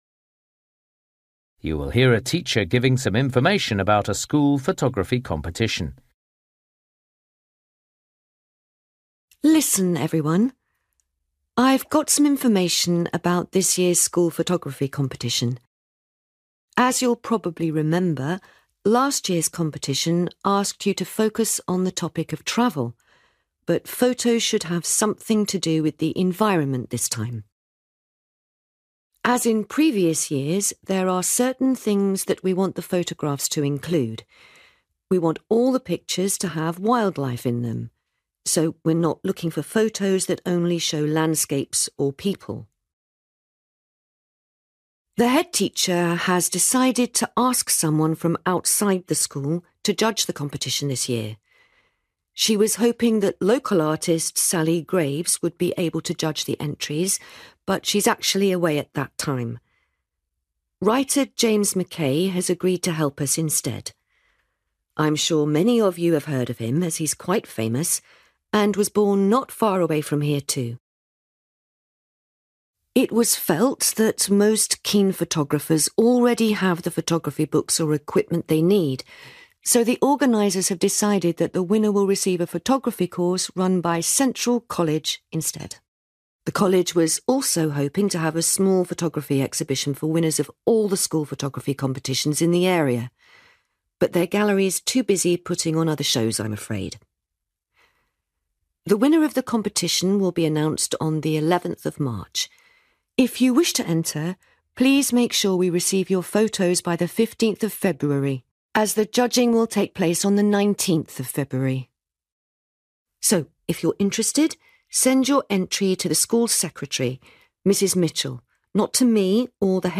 You will hear a teacher giving some information about a school photography competition.